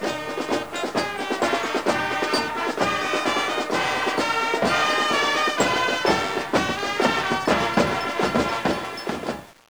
Marching Band